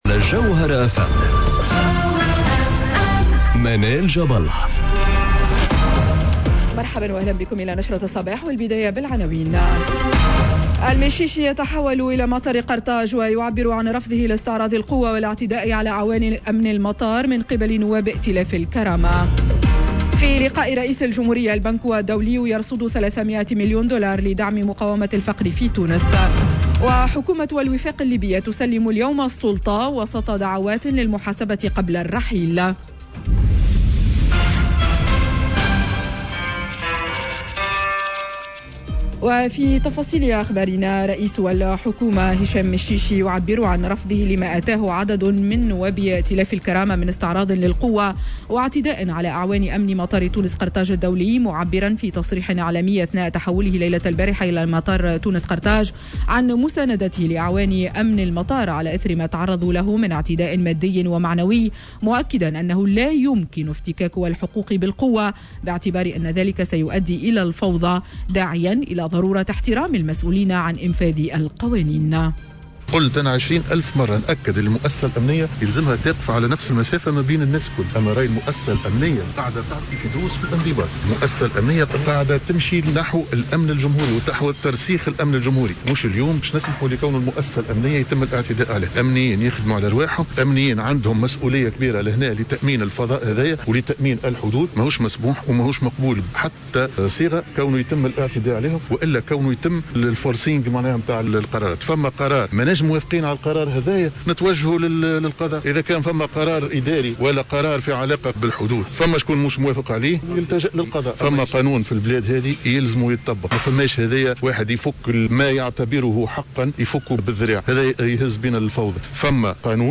نشرة أخبار السابعة صباحا ليوم الثلاثاء 16 مارس 2021